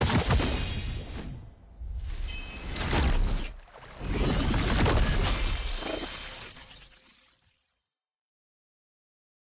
sfx-exalted-rolling-ceremony-multi-gold-anim.ogg